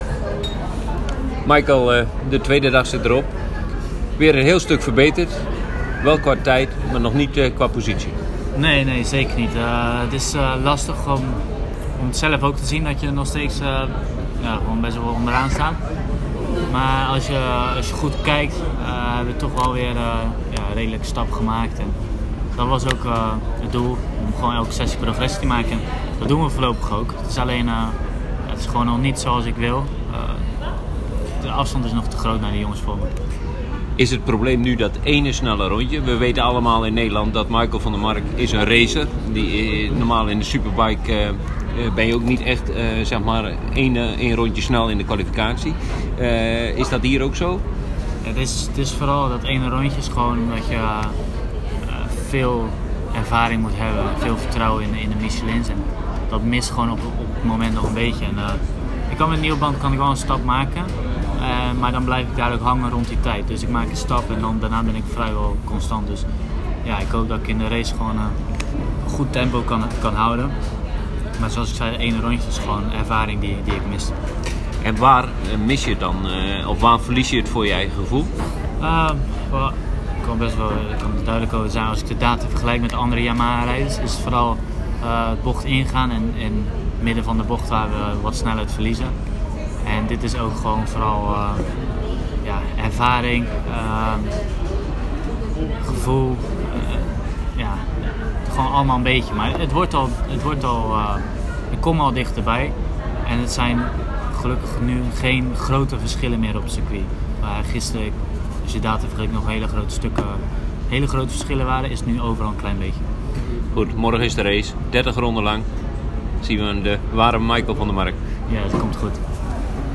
Na afloop van de kwalificatie spraken we met Van der Mark, beluister zijn verhaal.
Beluister de reactie van de Monster Yamaha Tech3 coureur direct na afloop van de kwalificatie via onderstaand audio bestand: